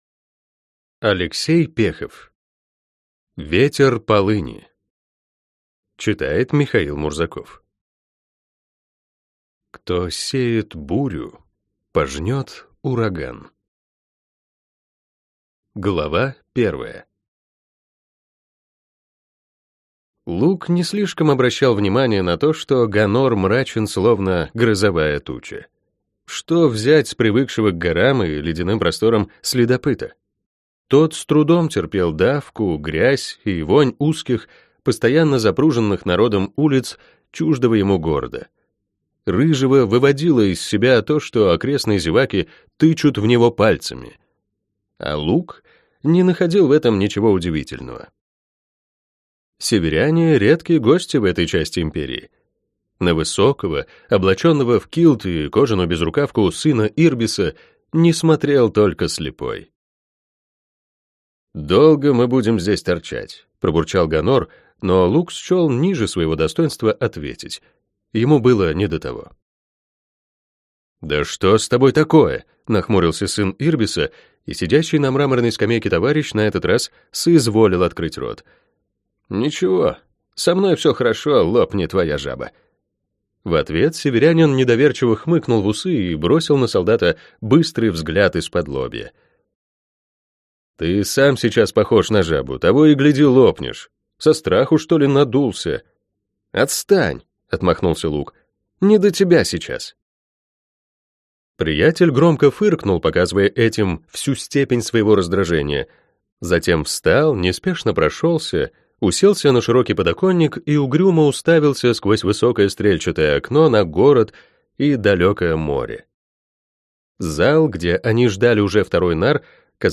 Аудиокнига Ветер полыни - купить, скачать и слушать онлайн | КнигоПоиск
Аудиокнига «Ветер полыни» в интернет-магазине КнигоПоиск ✅ в аудиоформате ✅ Скачать Ветер полыни в mp3 или слушать онлайн